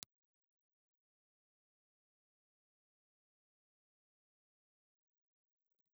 Ribbon
Impulse Response file of a Trix ribbon microphone.
Trix_Ribbon_IR.wav